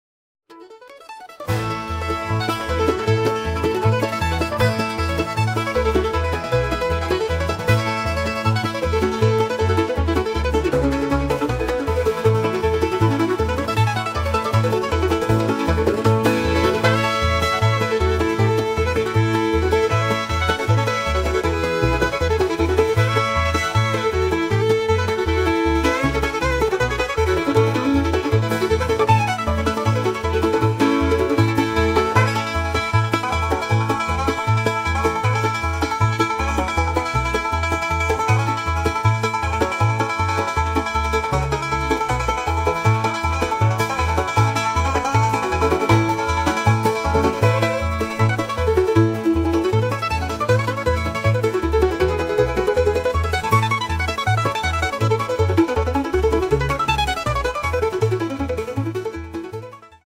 Flotte Country Musik im typischen Musikstyle.